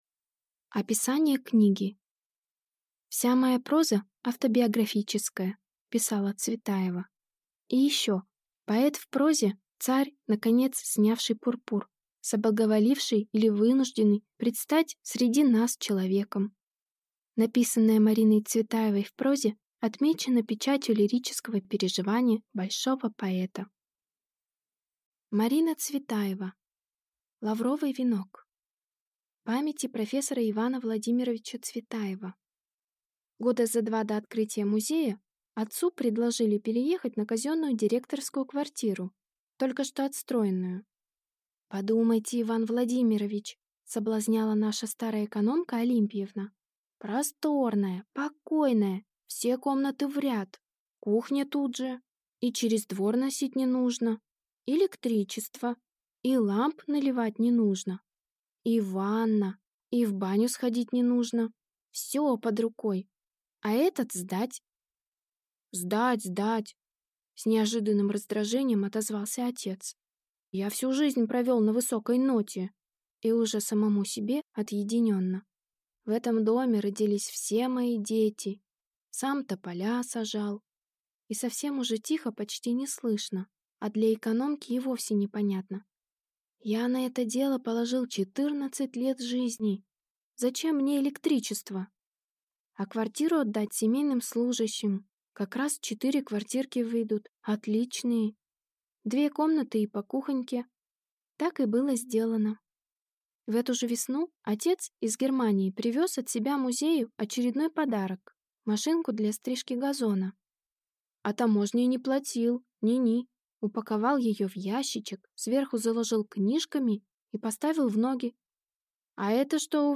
Аудиокнига Лавровый венок | Библиотека аудиокниг
Прослушать и бесплатно скачать фрагмент аудиокниги